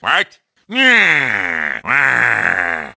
Wario growls in disappointment in Mario Kart Wii.